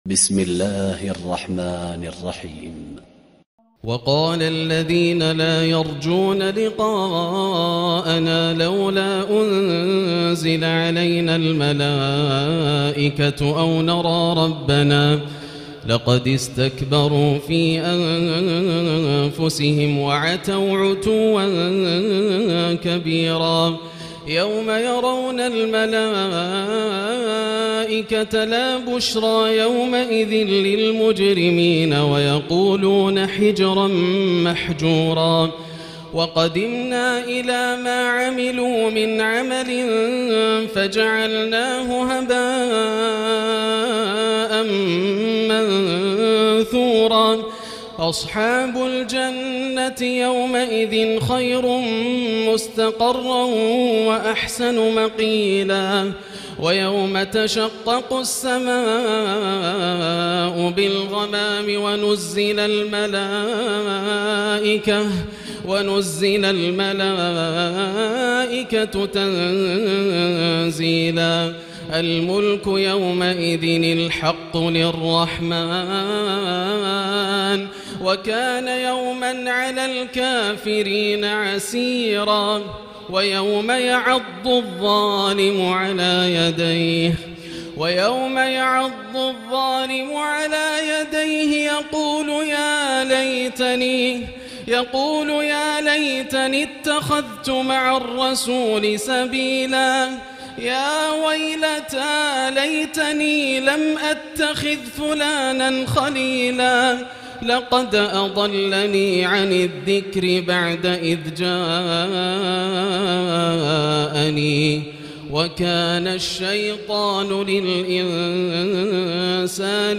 الليلة الثامنة عشر من سورتي الفرقان21-77 والشعراء1-104 > الليالي الكاملة > رمضان 1439هـ > التراويح - تلاوات ياسر الدوسري